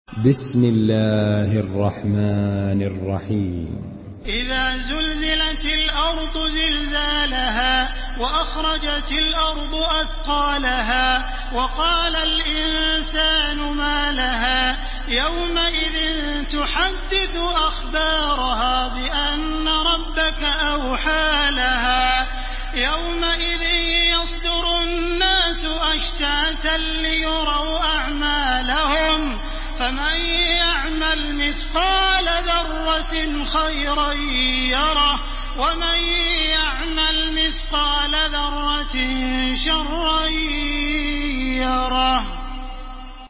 Makkah Taraweeh 1430
Murattal Hafs An Asim